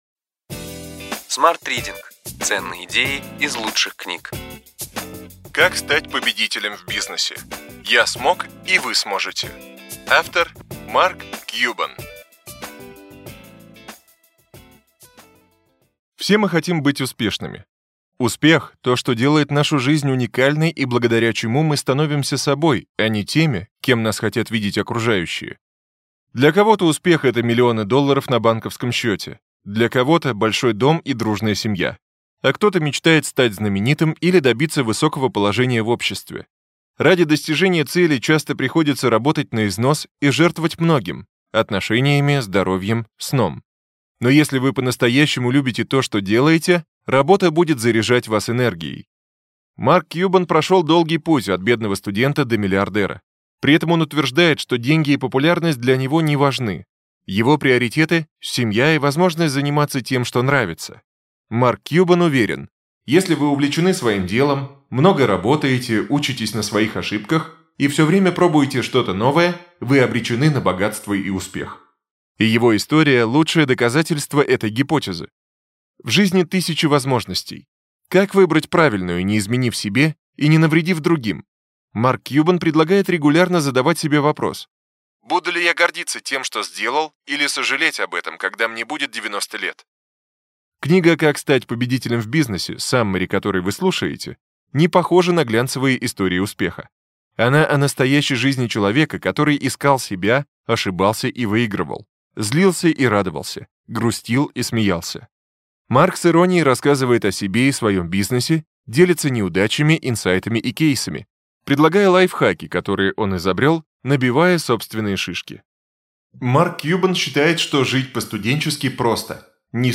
Аудиокнига Ключевые идеи книги: Как стать победителем в бизнесе. Я смог, и вы сможете. Марк Кьюбан | Библиотека аудиокниг